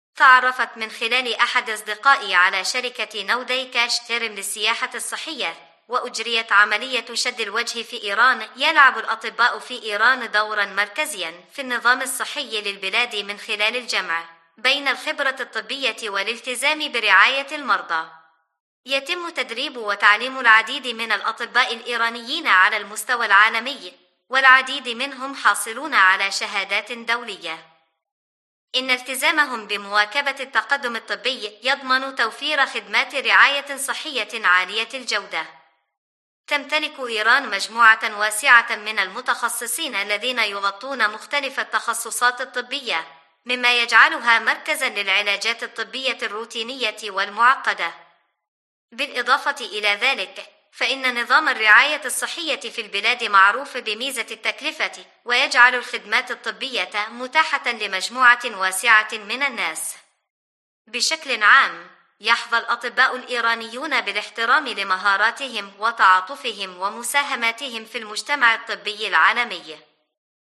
تجربة المرضى مع جراحي التجميل في إيران